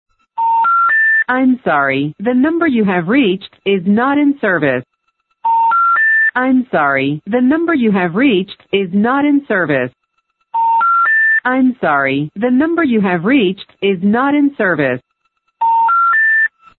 number not in service message.
NotInService.mp3